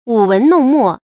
舞文弄墨 注音： ㄨˇ ㄨㄣˊ ㄋㄨㄙˋ ㄇㄛˋ 讀音讀法： 意思解釋： 舞：弄；墨：文筆。